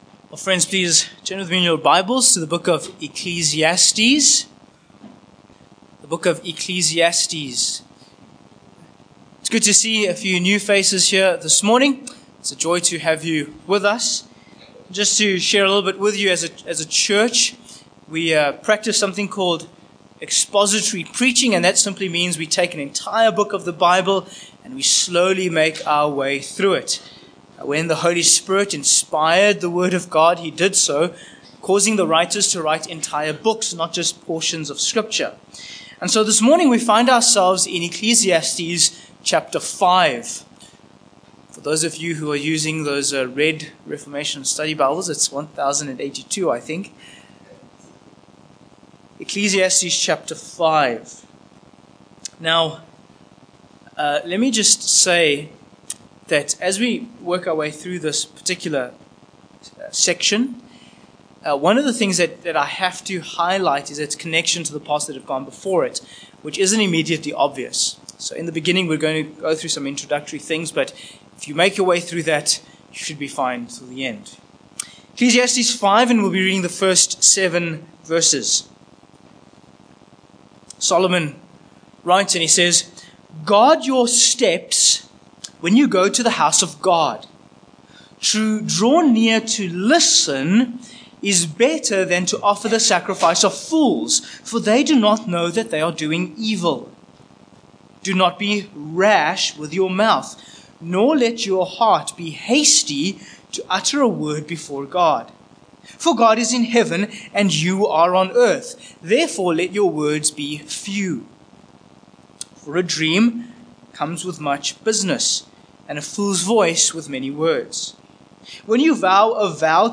Sermon Points 1. The Guardians of Worship v1